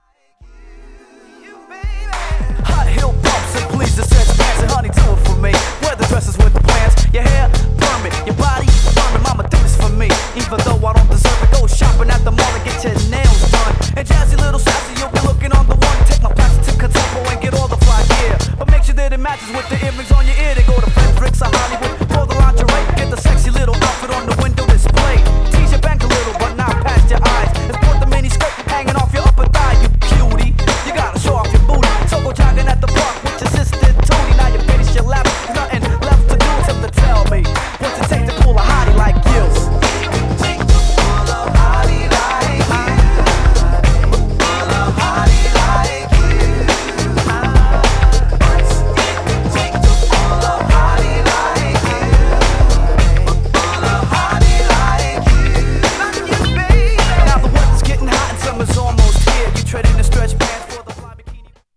Styl: Hip Hop